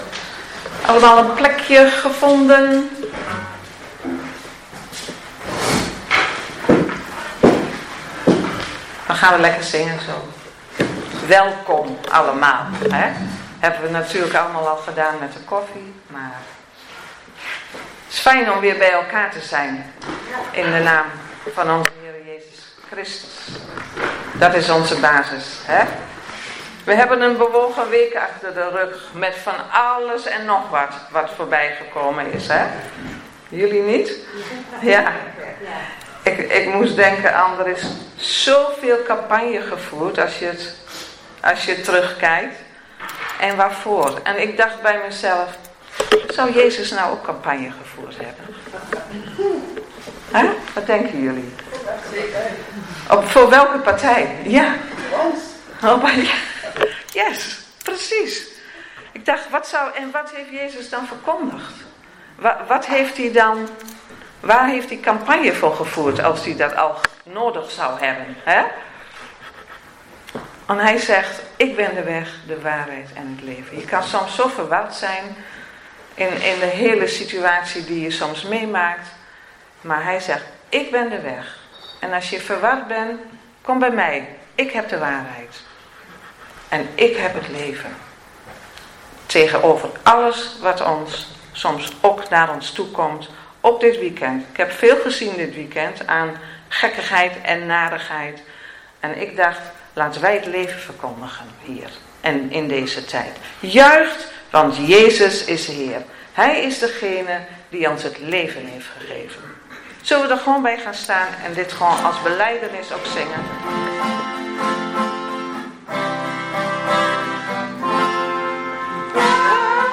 2 november 2025 dienst - Volle Evangelie Gemeente Enschede